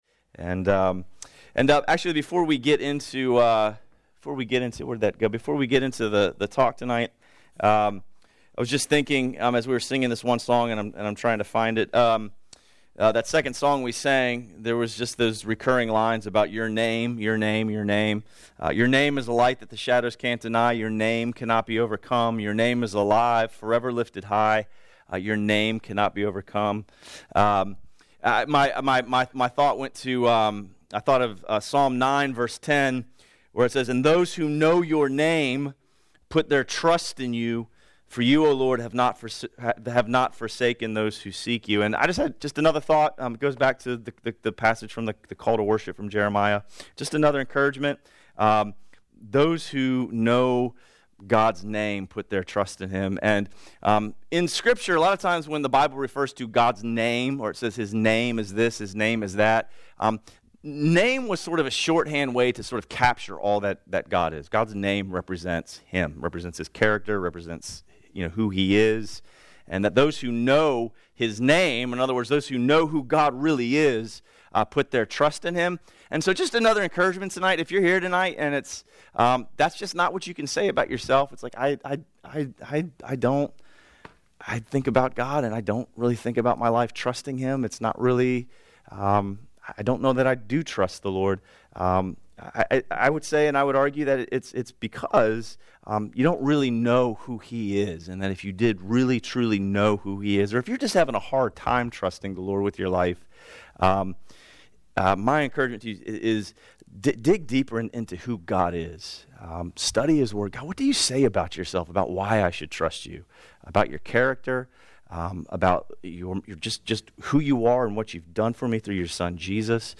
A message from the series "New Life in Jesus."